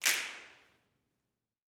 SNAPS 14.wav